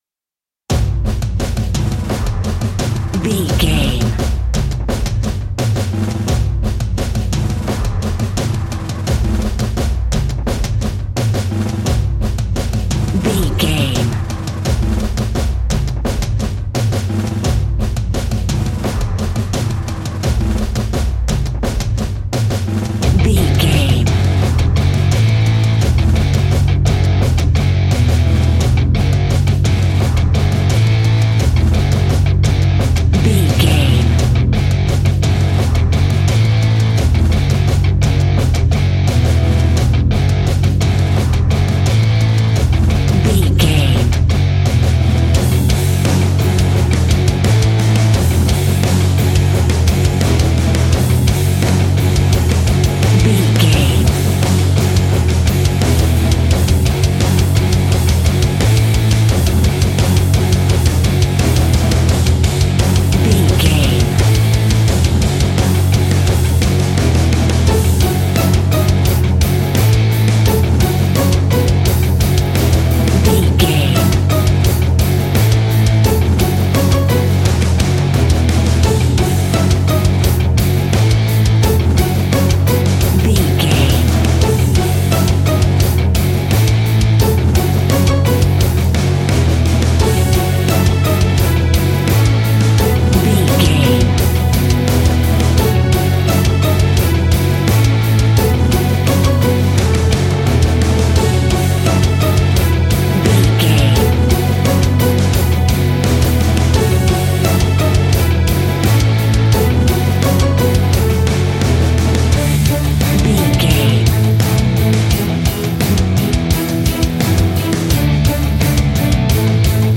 Epic / Action
Fast paced
Aeolian/Minor
F#
hard rock
heavy metal
scary rock
instrumentals
Heavy Metal Guitars
Metal Drums
Heavy Bass Guitars